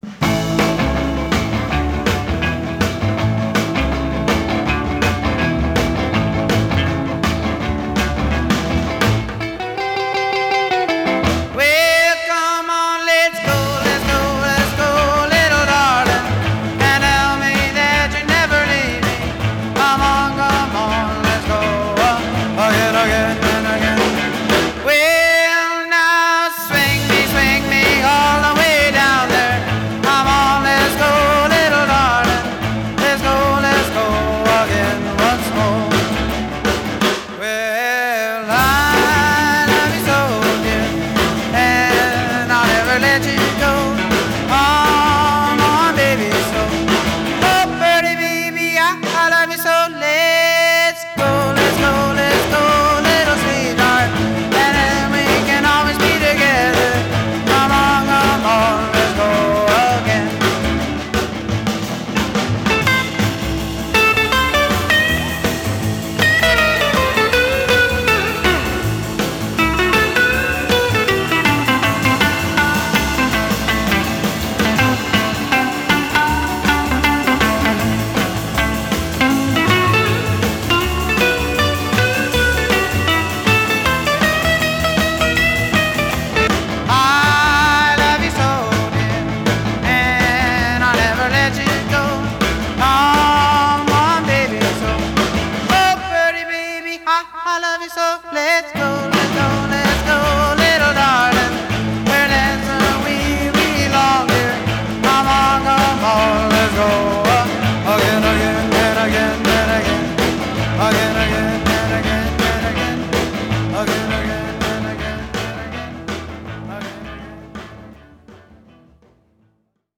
Жанр: Рок-н-ролл